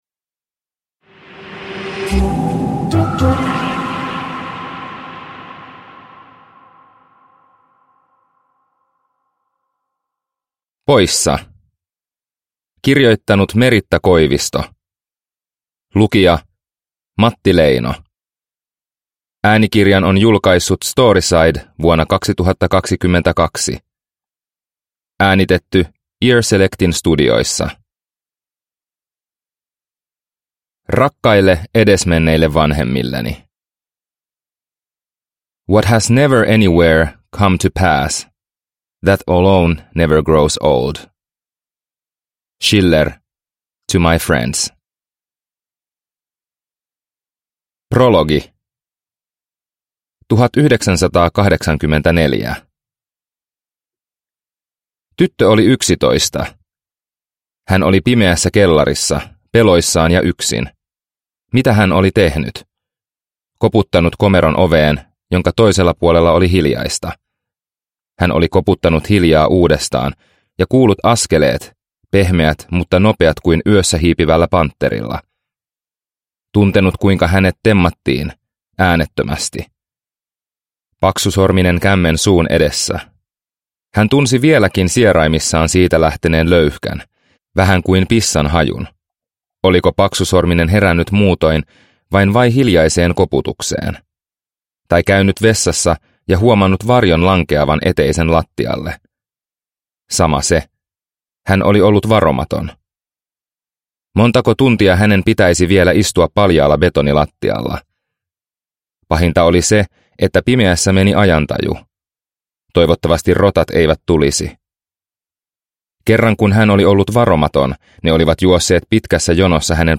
Poissa – Ljudbok – Laddas ner